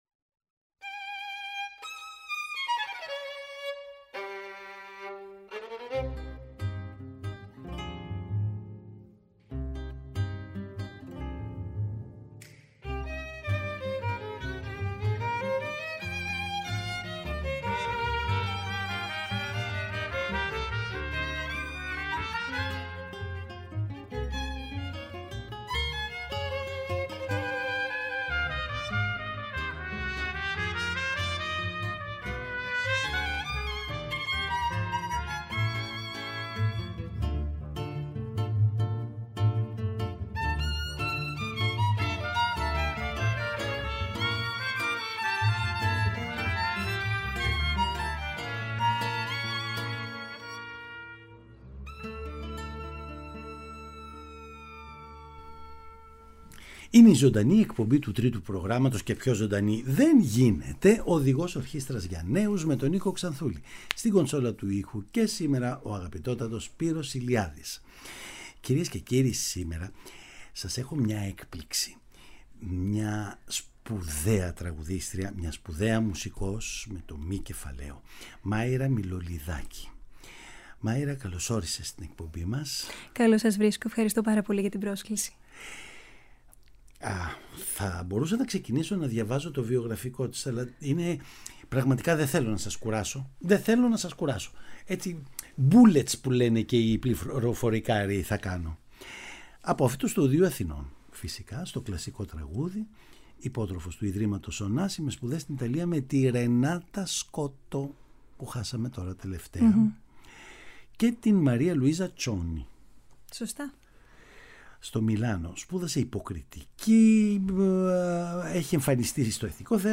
Η έλευσή της στην εκπομπή μας είναι αφορμή να ακούσουμε την υπέροχη φωνή της, το ευρύ ρεπερτόριο που ερμηνεύει αλλά και τη βαθιά γνώση της στην Τέχνη των ήχων.
Παραγωγή-Παρουσίαση: Νίκος Ξανθούλης